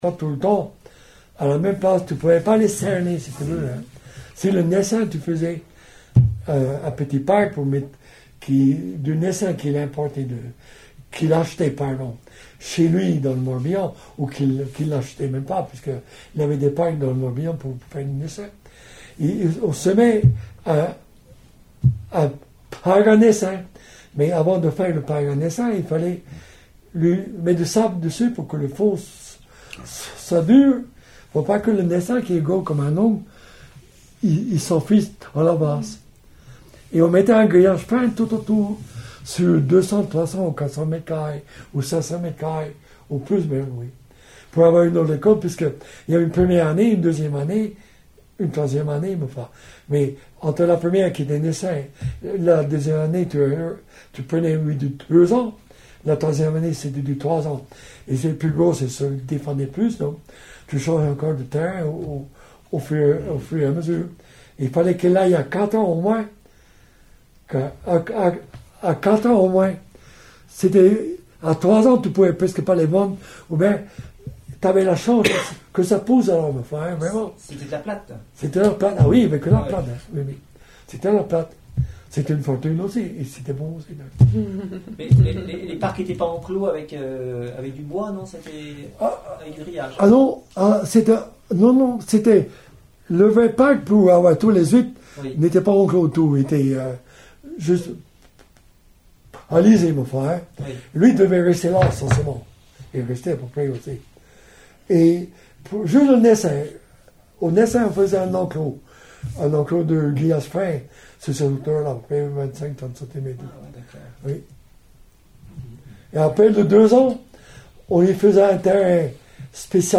Témoignage oral